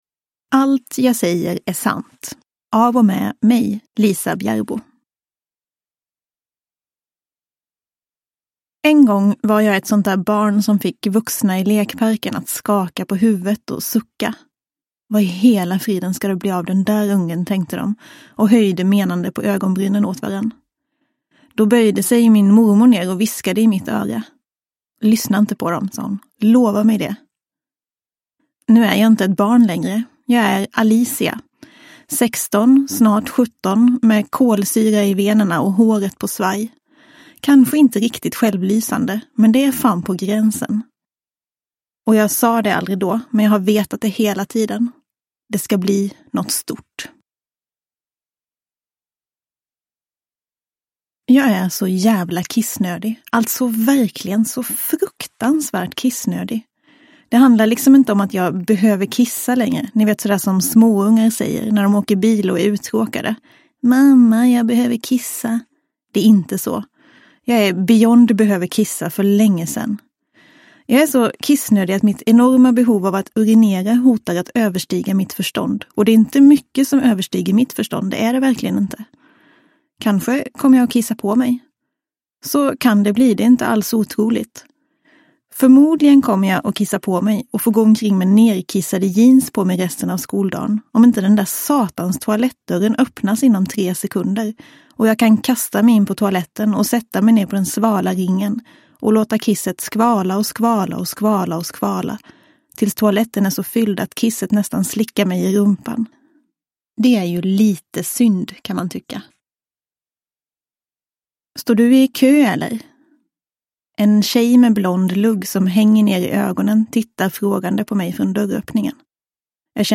Allt jag säger är sant – Ljudbok – Laddas ner